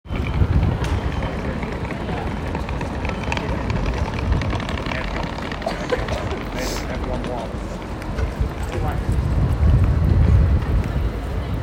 1. Suitcase strolling through the streets
Bristol-Shopping-Quarter.m4a